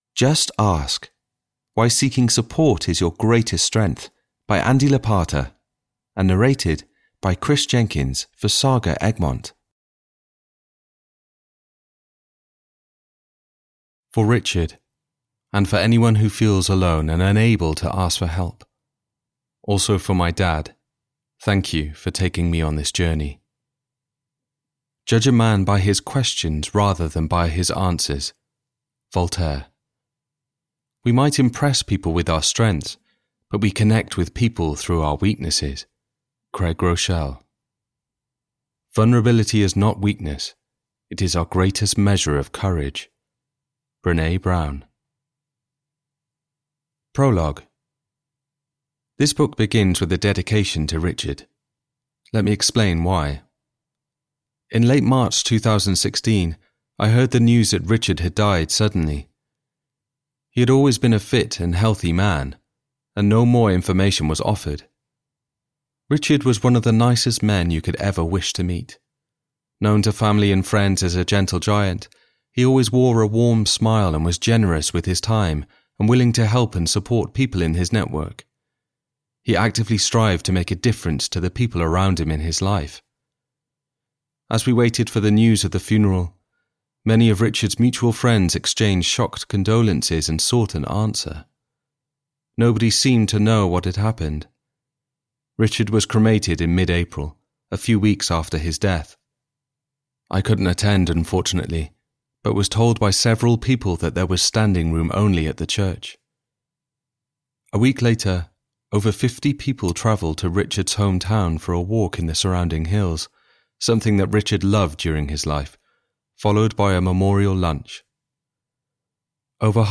Just Ask: Why Seeking Support is Your Greatest Strength (EN) audiokniha
Ukázka z knihy